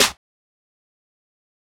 Waka Snare - 3 (5).wav